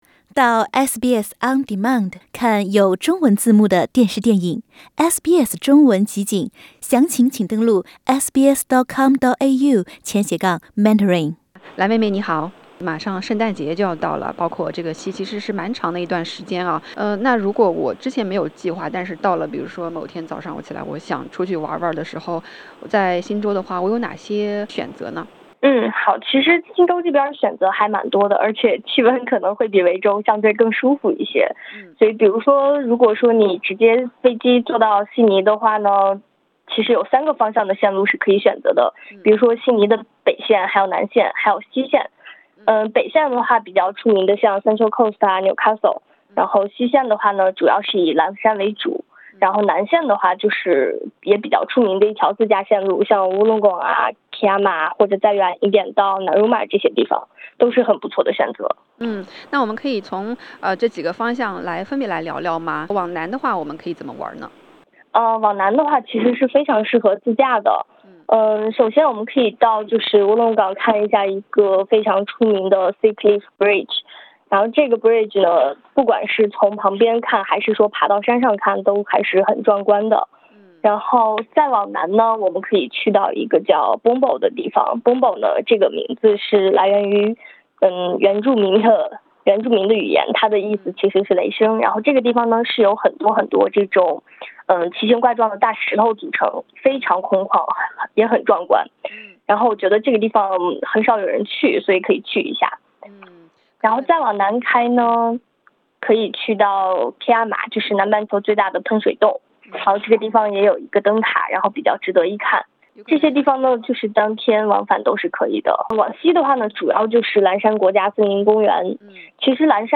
圣诞、新年将至，假期的某一天早晨醒过来，突发奇想开车自驾去周边转转，如果没有事先规划，有哪些路线可以参考呢？点击上方图片收听采访。